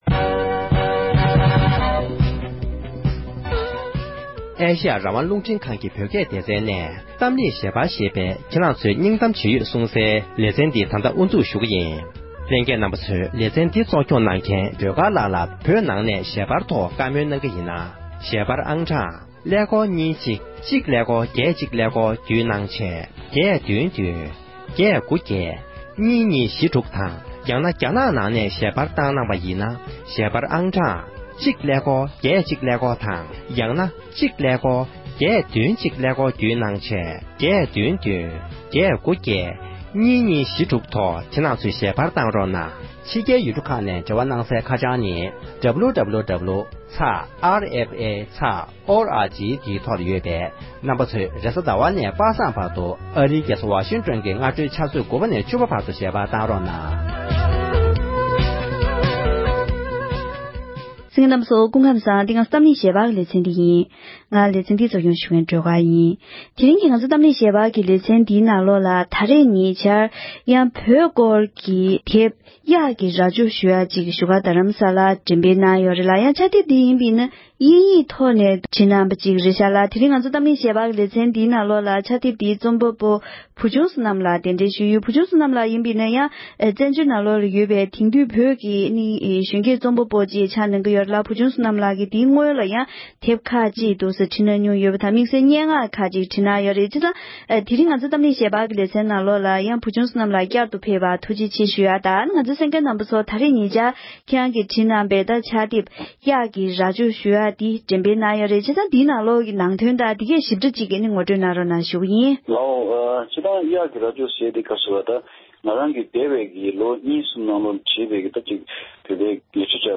བཀའ་མོལ་ཞུས་པ་ཞིག་གསན་རོགས་ཞུ༎